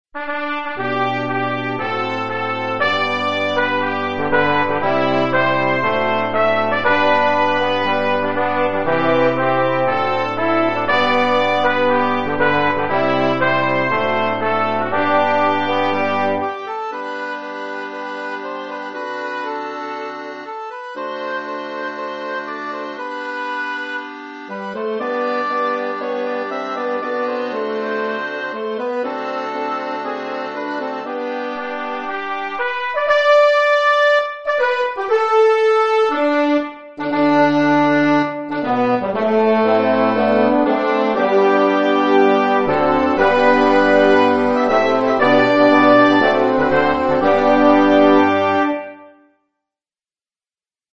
citoyinstrum.mp3